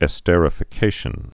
(ĕ-stĕrə-fĭ-kāshən)